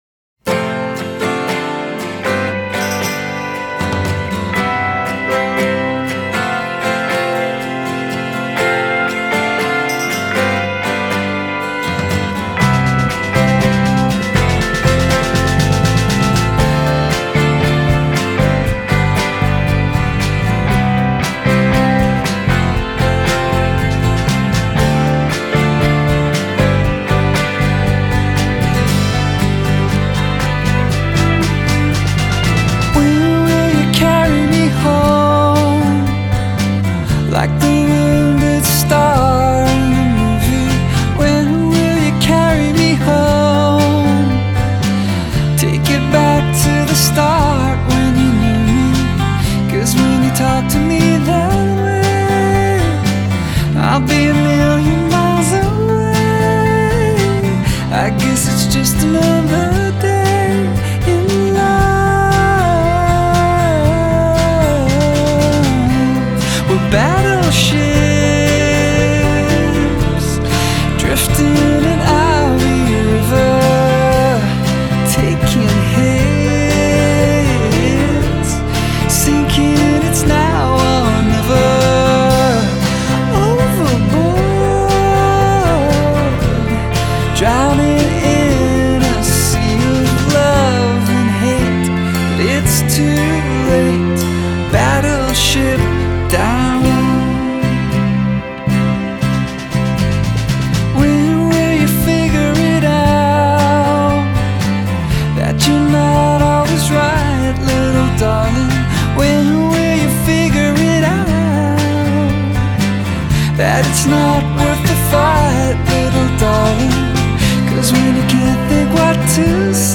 Indie Indie pop Alternative rock